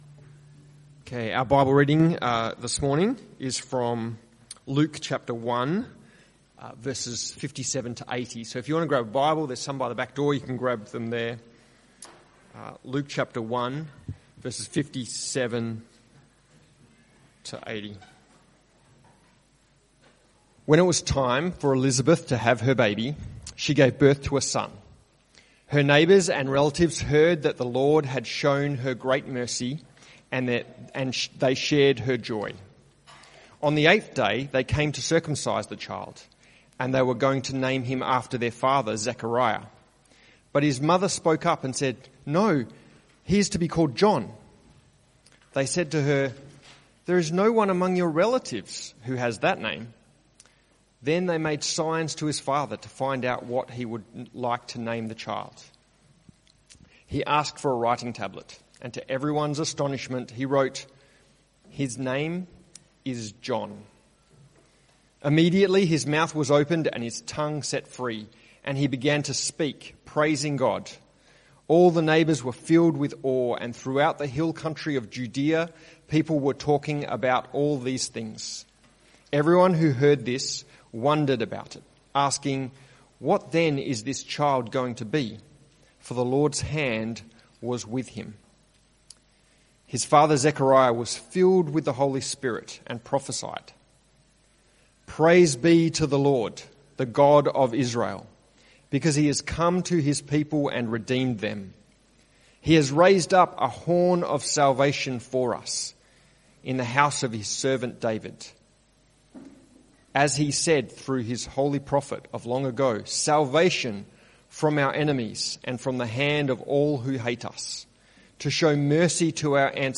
CBC Service: 21 Dec 2025 Series
Type: Sermons